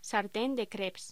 Locución: Sartén de crêpes
locución
Sonidos: Voz humana